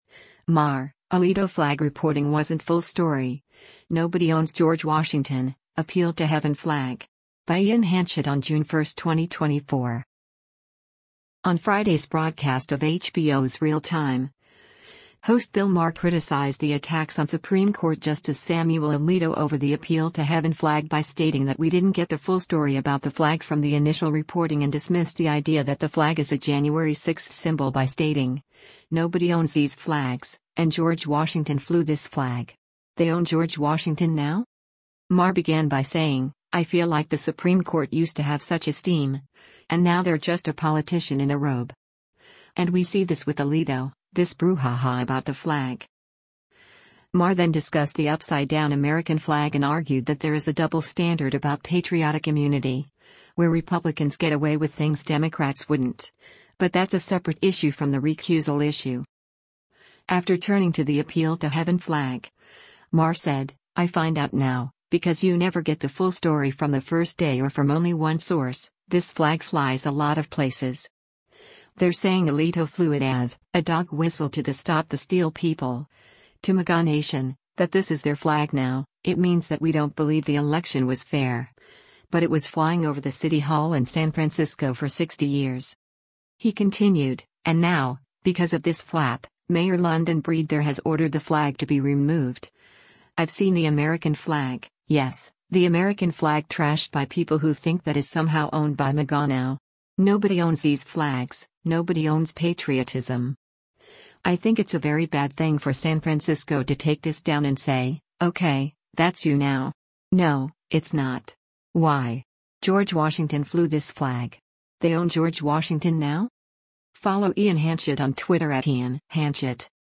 On Friday’s broadcast of HBO’s “Real Time,” host Bill Maher criticized the attacks on Supreme Court Justice Samuel Alito over the “Appeal to Heaven” flag by stating that we didn’t get the full story about the flag from the initial reporting and dismissed the idea that the flag is a January 6 symbol by stating, “Nobody owns these flags,” and “George Washington flew this flag. They own George Washington now?”